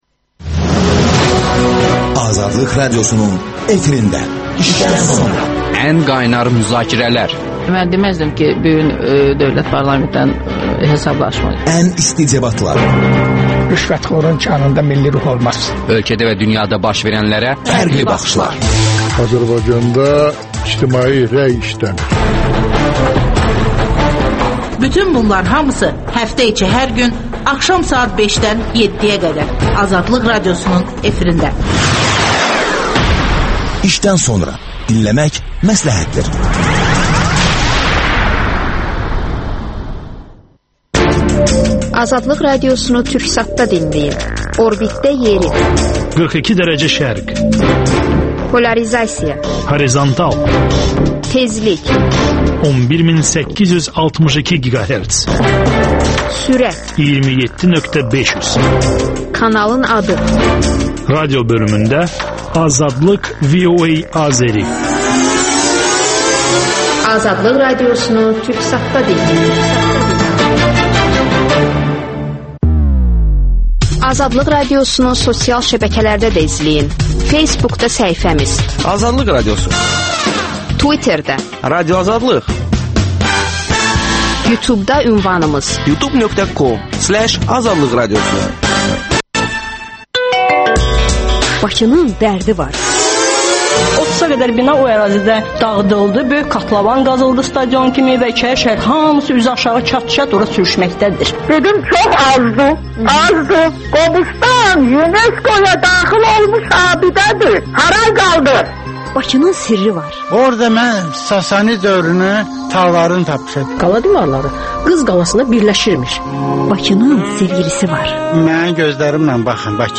Tarixçi-alim Cəmil Həsənli ilə ölkədə və dünyada baş verən proseslər barədə açıq söhbət